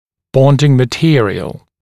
[‘bɔndɪŋ mə’tɪərɪəl][‘бондин мэ’тиэриэл]клеящий материал